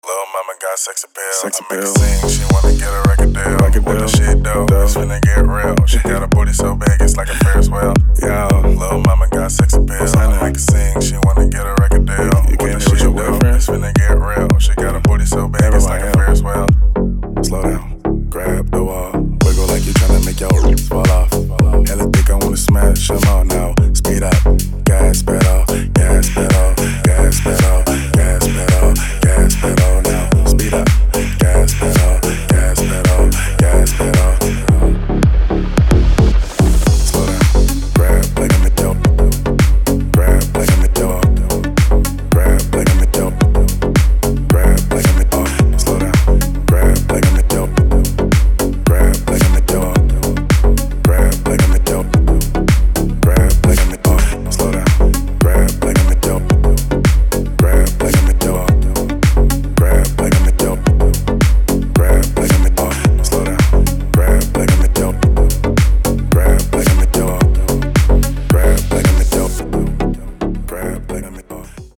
• Качество: 224, Stereo
мужской вокал
dance
Brazilian bass
G-House